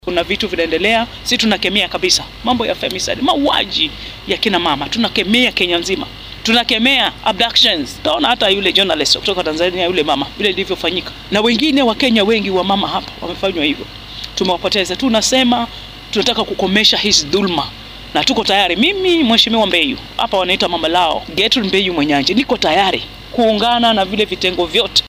DHAGEYSO: Wakiilka haweenka ee Kilifi oo ka hadashay afduubyada